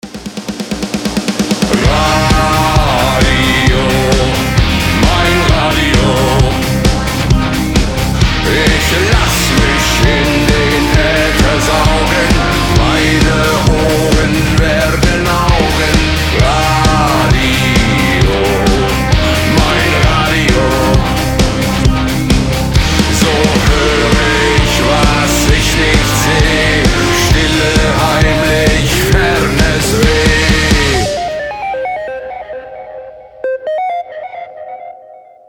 • Качество: 320, Stereo
громкие
мощные
Драйвовые
эпичные
Industrial metal
Neue Deutsche Harte
Мощная метал-музыка для любителей звонка потяжелее